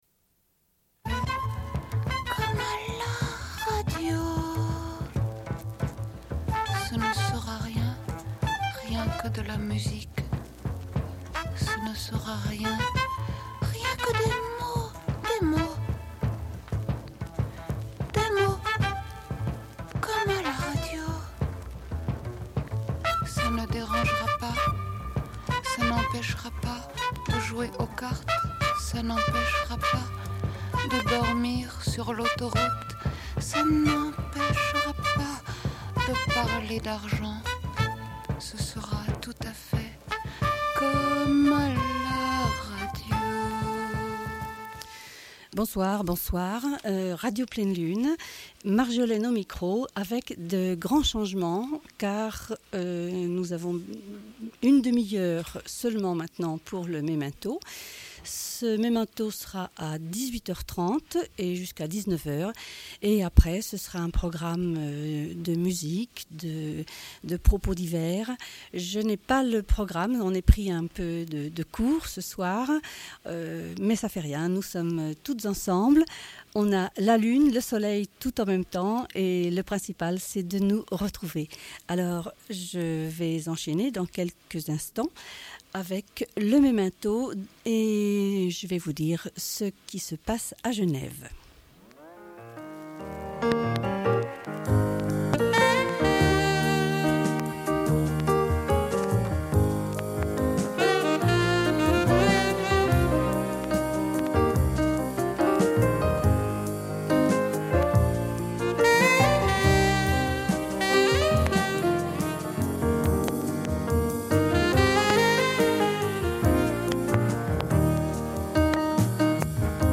Bulletin d'information de Radio Pleine Lune du 16.06.1993 - Archives contestataires
Une cassette audio, face B31:19